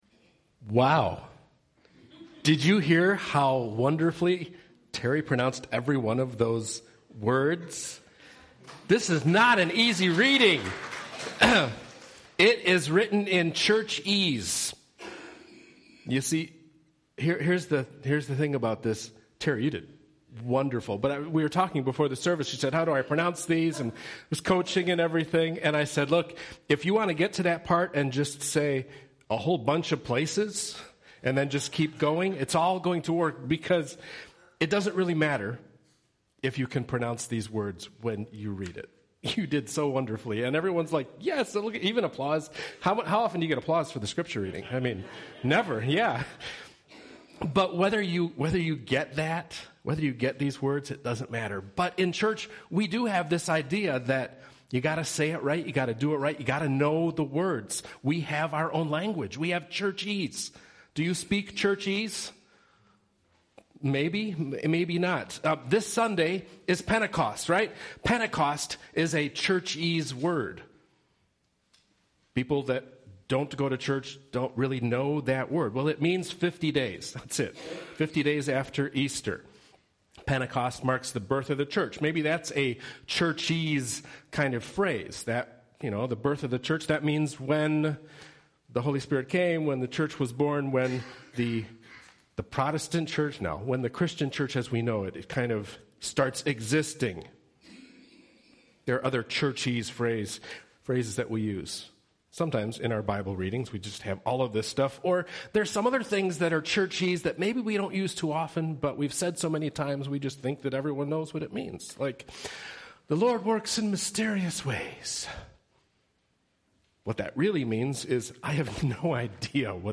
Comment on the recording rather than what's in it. Pentecost and Mental Health Sunday 2018 sermon from St. Paul's UCC in Downers Grove, IL.